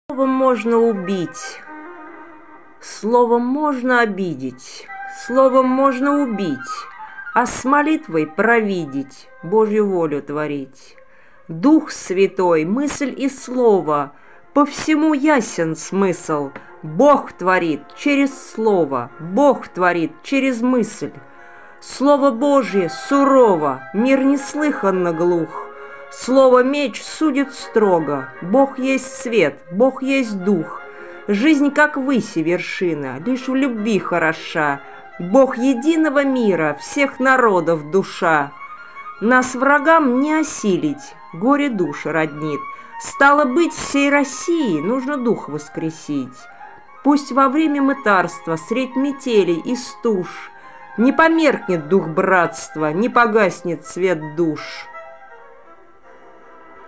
Музыка классики Озвучка автора Вивальди